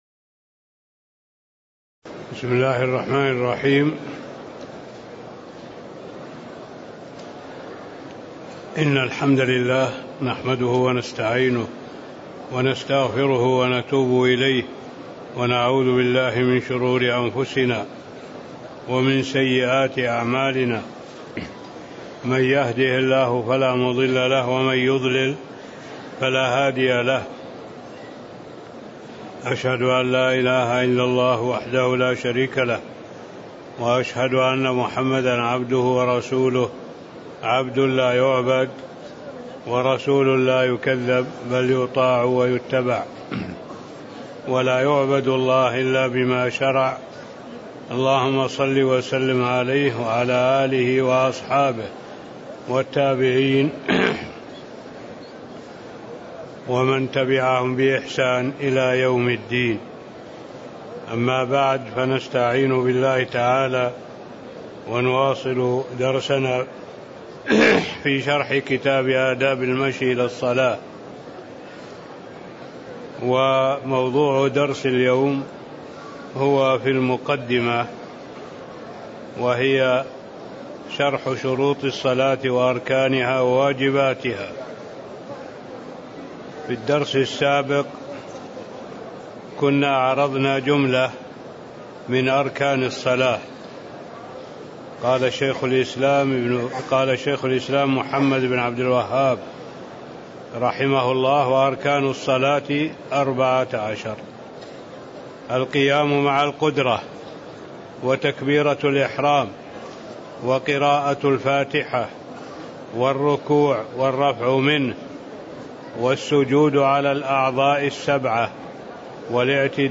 تاريخ النشر ٢٥ ذو الحجة ١٤٣٥ هـ المكان: المسجد النبوي الشيخ: معالي الشيخ الدكتور صالح بن عبد الله العبود معالي الشيخ الدكتور صالح بن عبد الله العبود أركان الصلاة (04)